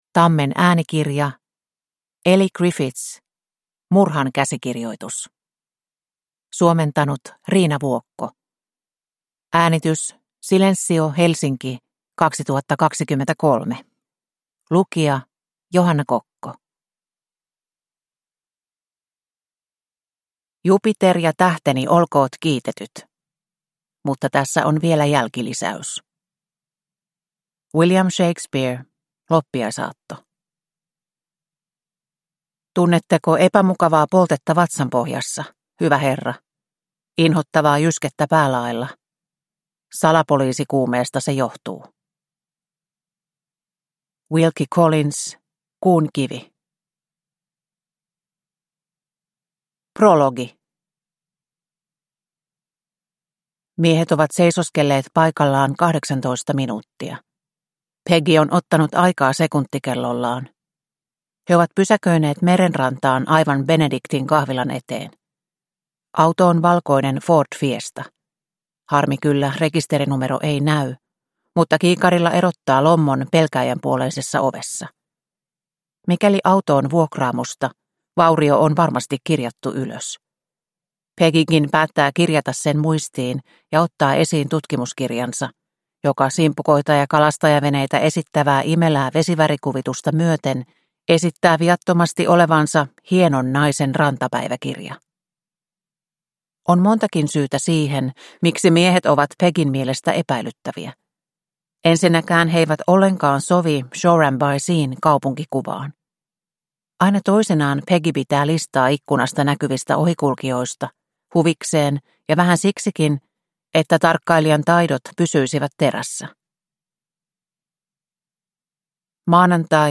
Murhan käsikirjoitus – Ljudbok – Laddas ner